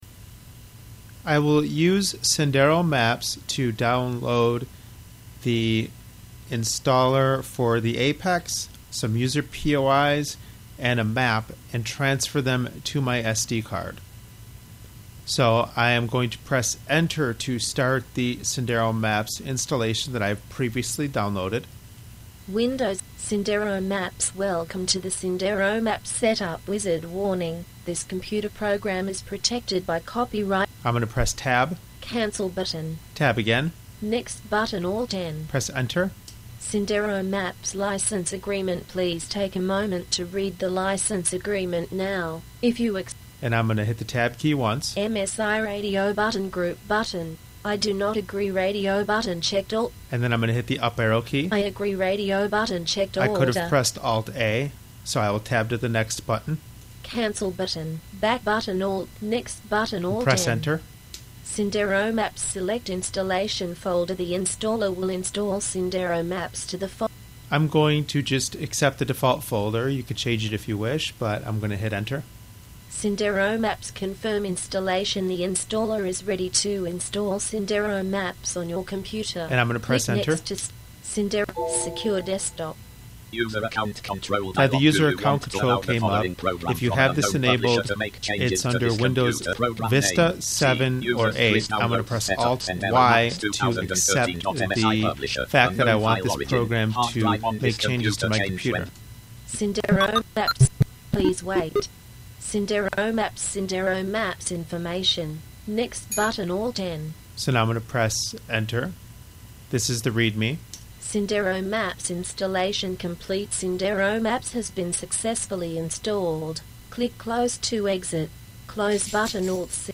Sendero GPS Audio Tutorial